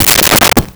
Cabinet Door Close 02
Cabinet Door Close 02.wav